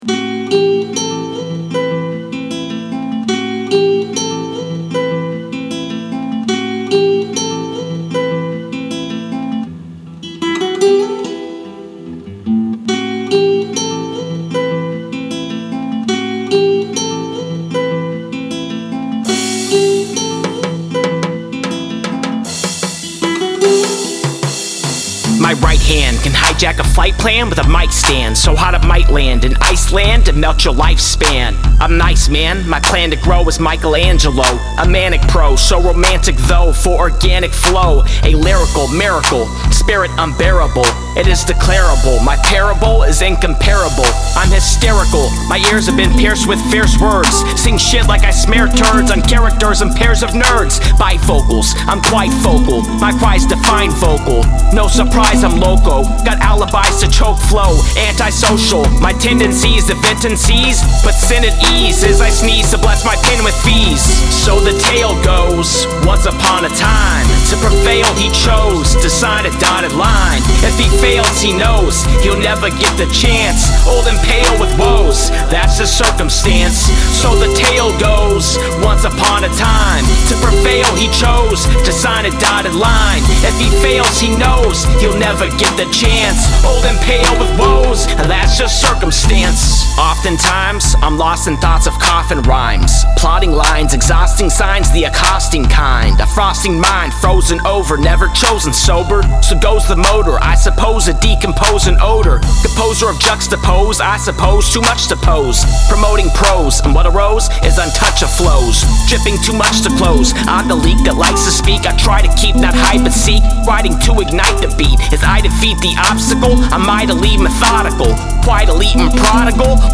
Recorded at AD1 Studios